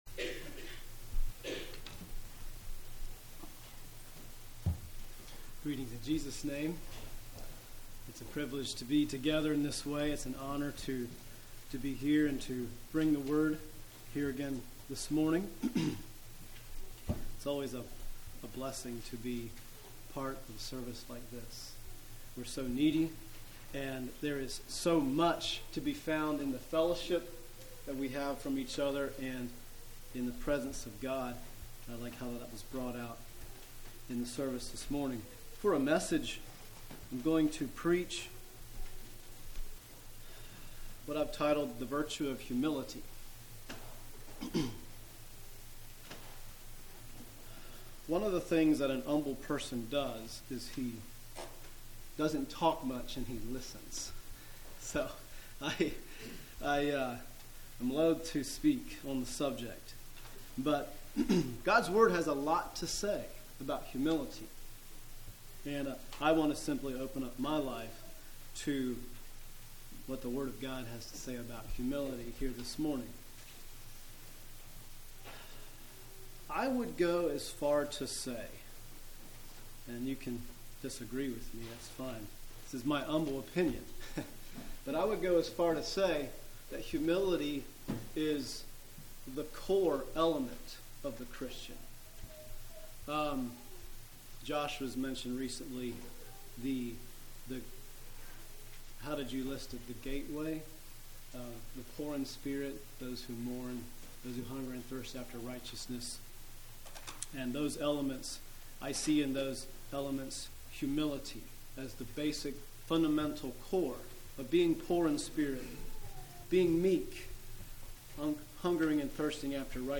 Play Now Download to Device The Virtue Of Humility Congregation: Pensacola Speaker